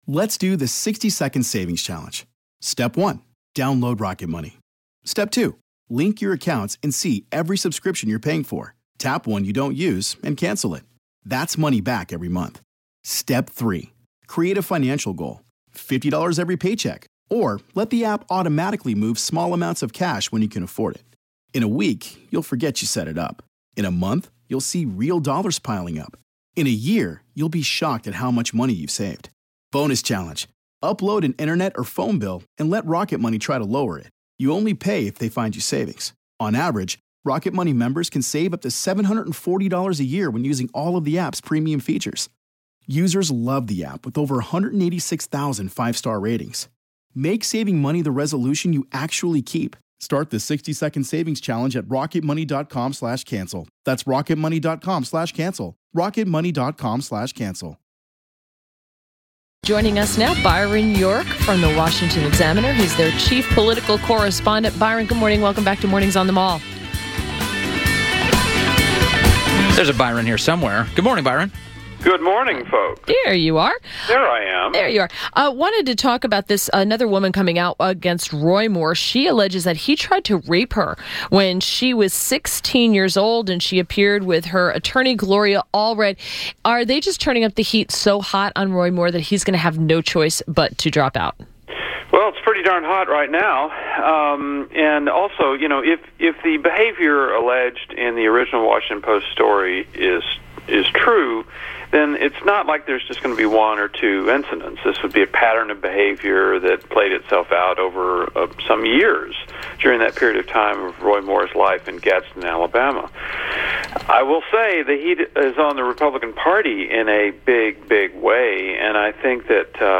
WMAL Interview - BYRON YORK - 11.14.17
INTERVIEW -- BYRON YORK - the chief political correspondent for the Washington Examiner• Thoughts on senators pushing to get Roy Moore to quit• RUSSIA: Byron York: Did dossier trigger the Trump-Russia probe?